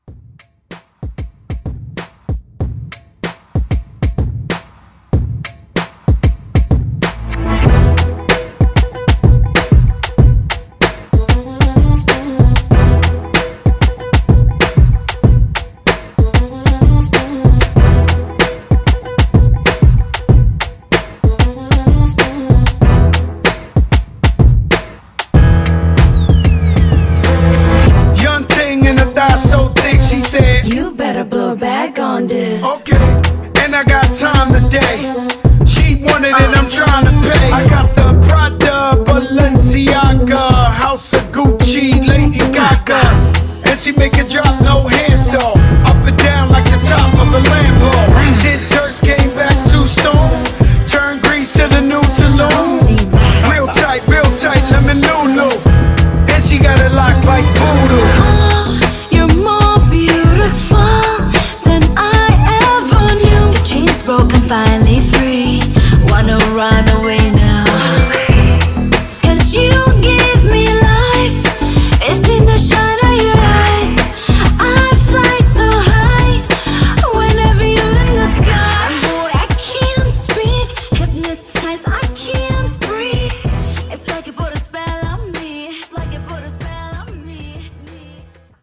Genres: HIPHOP , RE-DRUM
Clean BPM: 95 Time